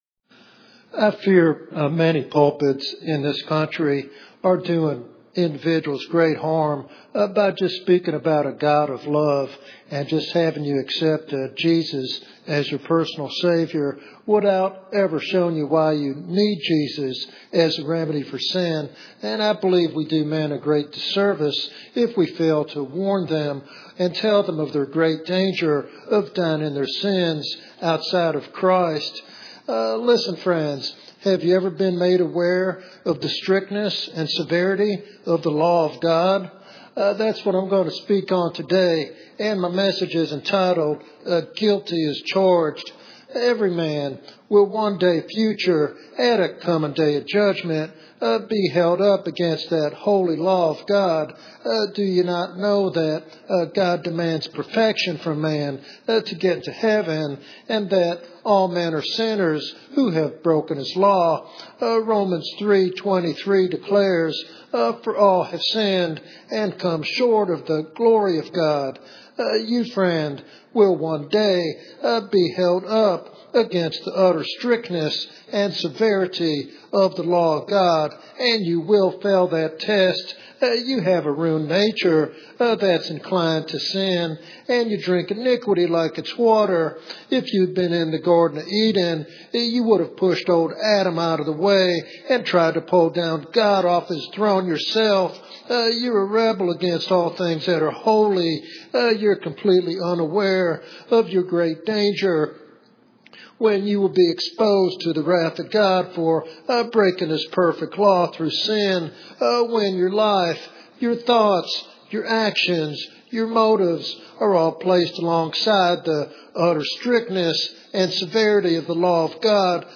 This evangelistic sermon challenges individuals to confront their sin and embrace God's mercy before it is too late.